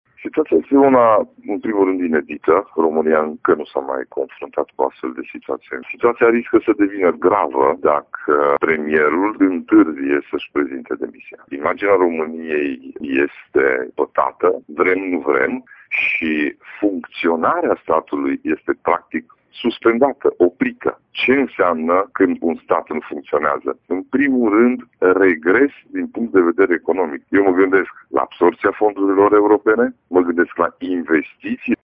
Situația riscă să devină gravă dacă premierul întârzie să-și prezinte demisia, spune președintele PNL Mureș, Ciprian Dobre: